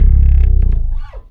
10BASS01  -R.wav